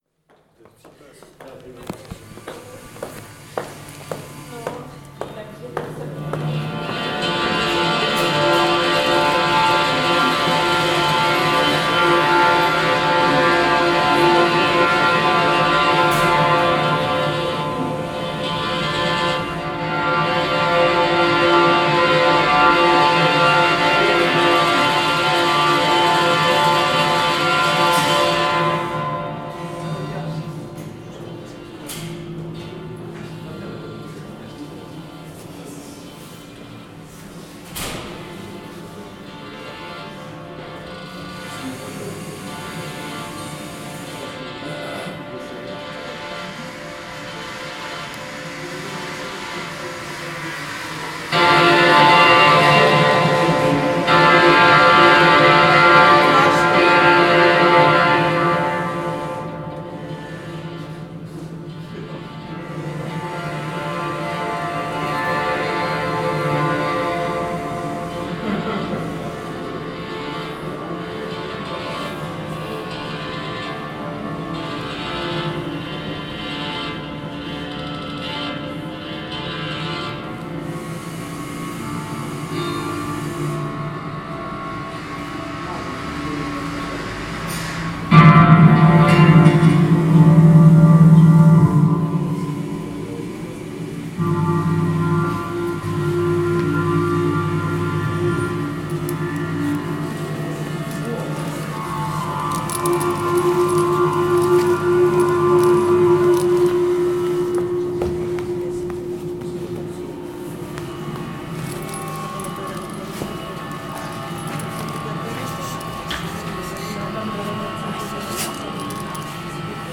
Tagy: stroje umění galerie pasáže
Instalace v dlouhé výkladní skříni spočívala v zavěšené kovové tyči s vnitřní drážkou po níž se pohybuje kovová koule. Servomotorky a navijáky upevněné na obou koncích týče střídavě zvedají a spouští tyč. takže se koule pohybuje z jedné strany na druhou. Během akce byl zvuk z vnitřku výlohy snímaný dvěma mikrofony a reprodukován zesilený do prostoru pasáže..